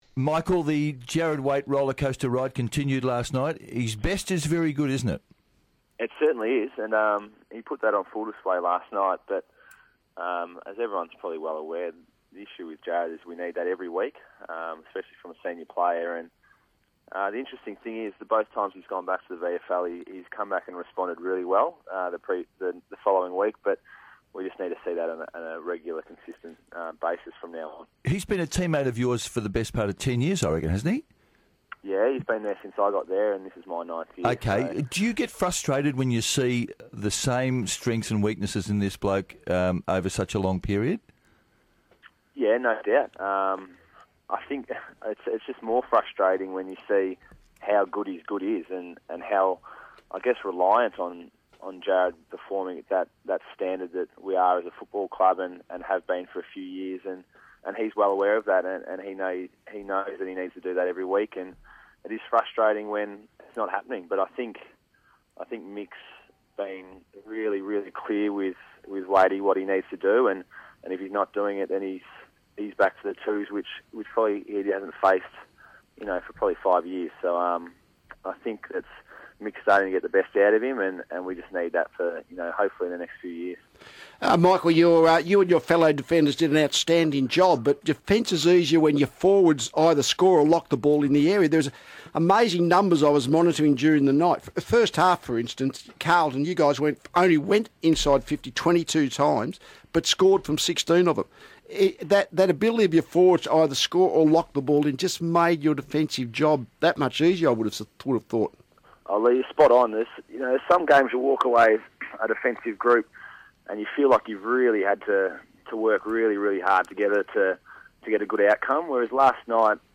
Deputy Vice-Captain Michael Jamison chats to 3AW's Mike Sheahan and Leigh Matthews about Carlton's Round 18 win over the Kangaroos.